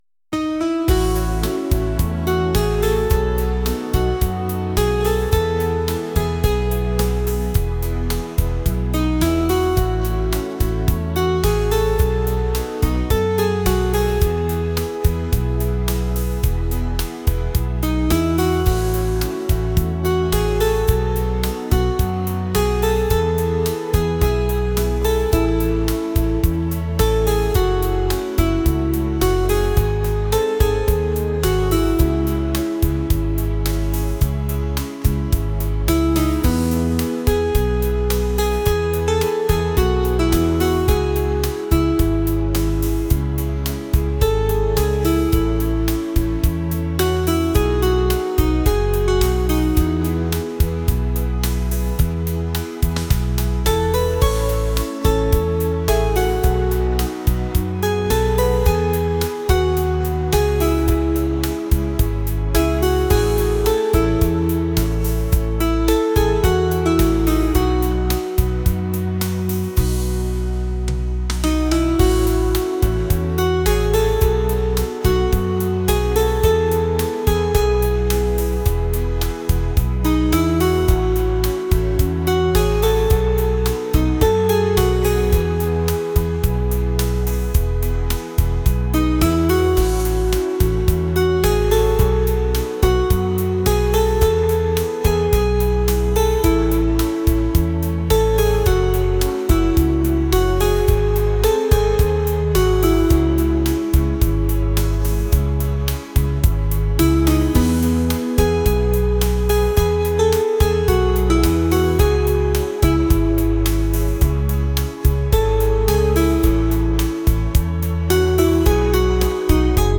pop | acoustic | ambient